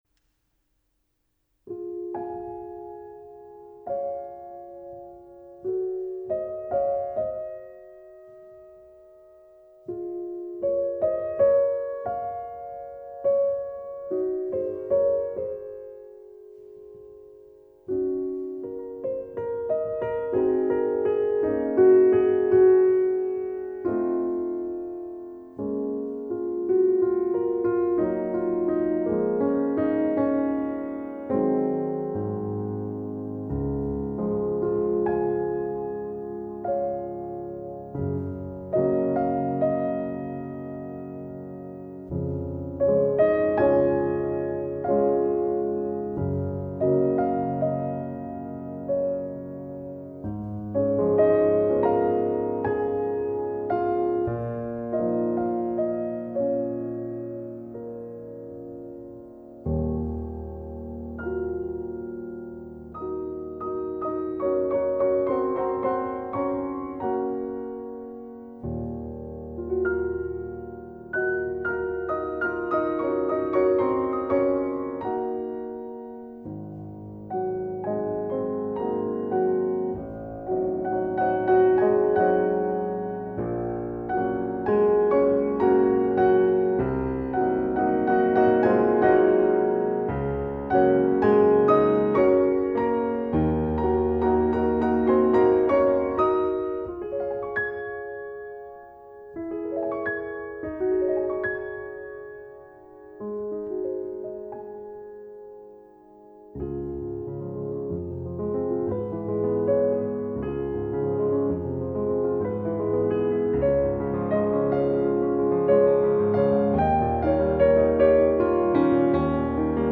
今回は響きが素晴らしくて、高音部が響くだけでなく、
最初の入りから音色が違って、甘やかで艶っぽさがあるように感じました。
私は聴き慣れていない素人ですが、音がクリアで、聴こうとしなくてもメロディーやいろいろ耳に飛び込んでくる気がしました。